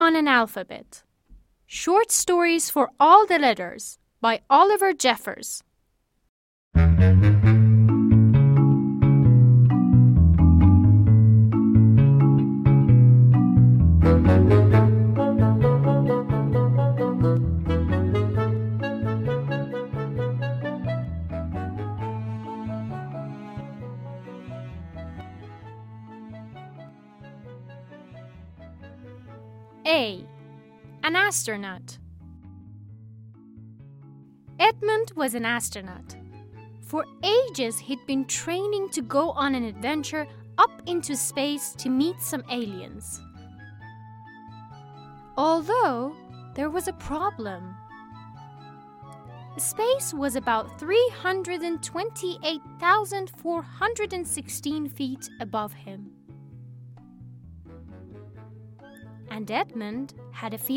کتاب صوتی انگلیسی Once upon an Alphabet | مرجع دانلود زبان